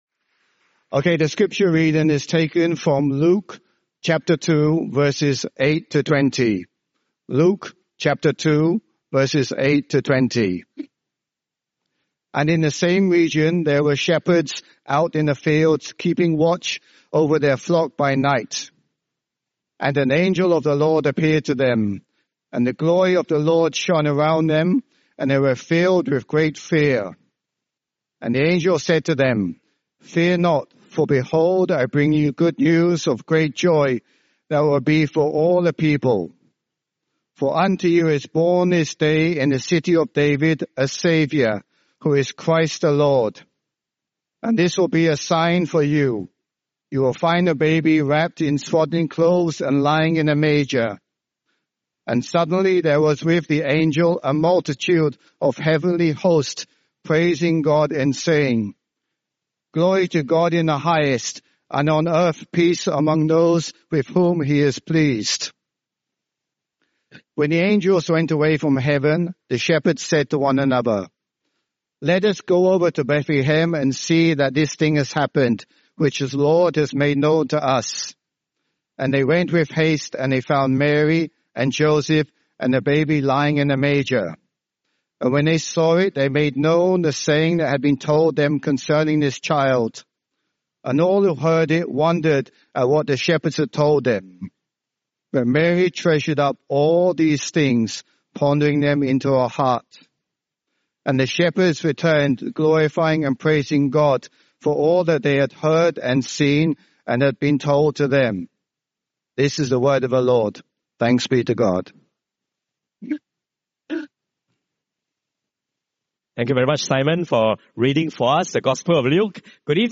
5.30pm Christmas Eve Gospel Service with nativity play by the Junior Church children Luke 2:8-10